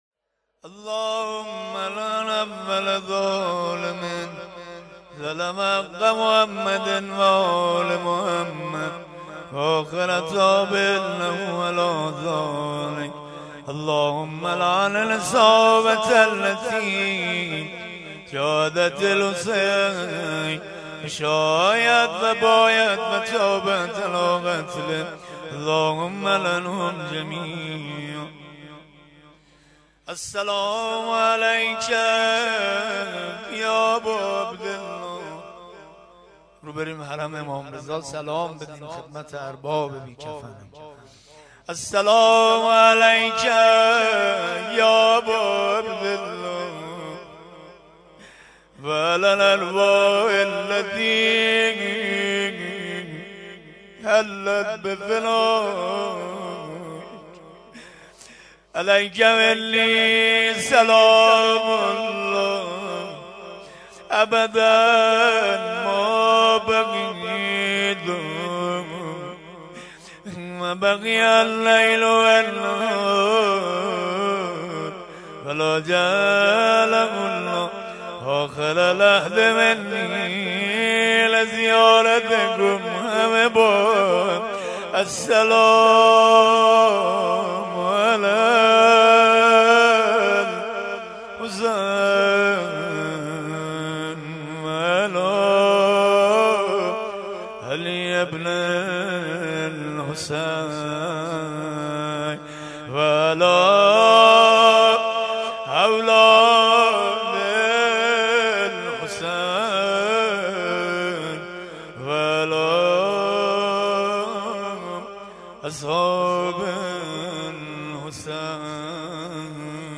مراسم این هفته قرائت زیارت عاشورا در هیئت رزمندگان غرب تهران برگزار شد.